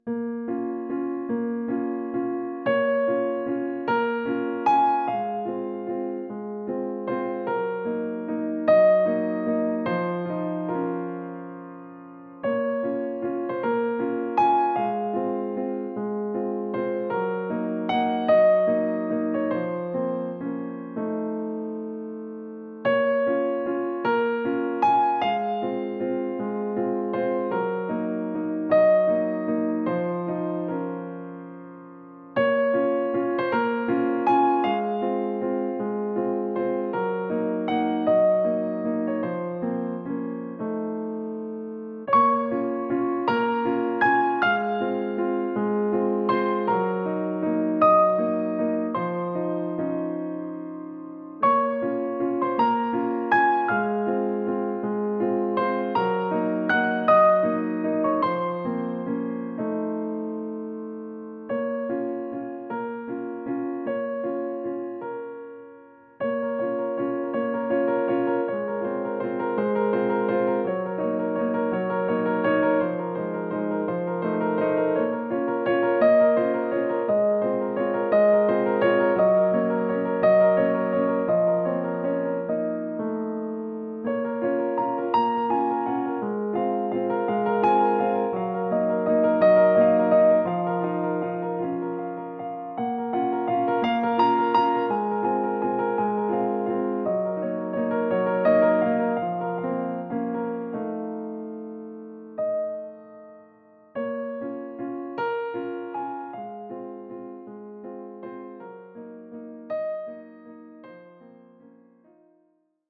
eine musikalische Lesung